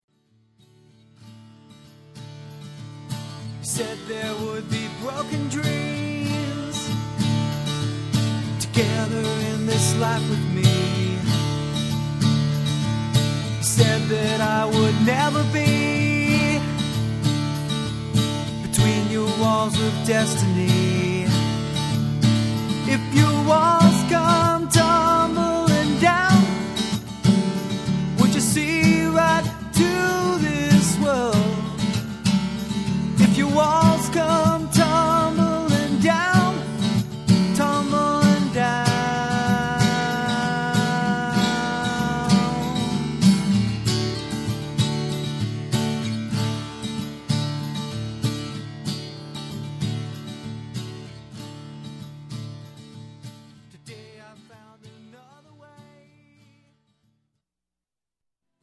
Solo - Live Demos
Original Live Demos